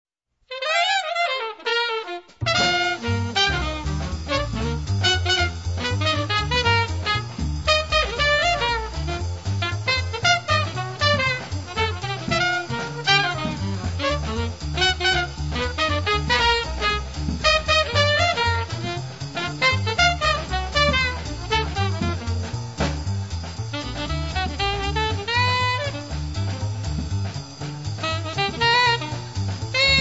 • free jazz
• jazz moderno